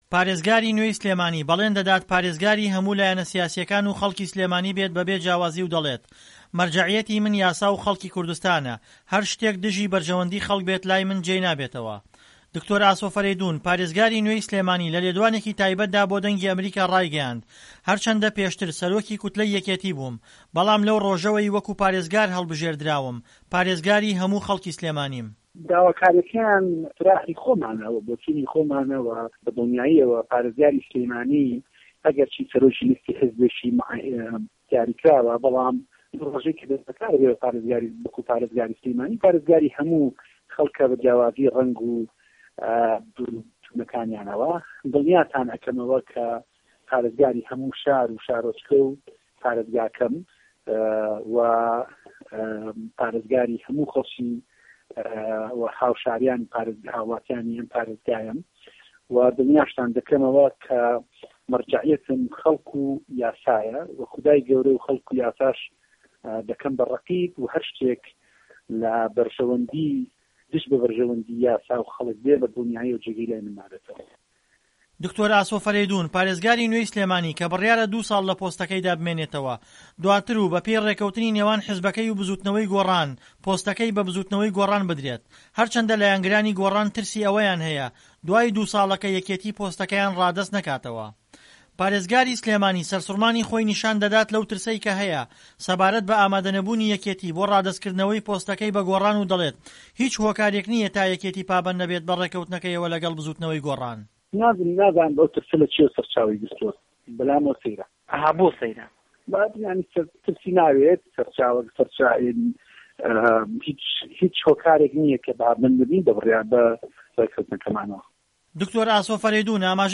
دکتۆر ئاسۆ فەرەیدون پارێزگارى نوێى سلێمانى، لە لێدوانێکى تایبەتدا بۆ دەنگى ئەمه‌ریکا رایگەیاند"هەرچەندە پێشتر سەرۆکى کوتلەى یەکێتى بووم ، بەڵام لەو رۆژەوەى وەکو پارێزگار هەڵبژێردراوم پارێزگارى هەموو خەڵکى سلێمانیم."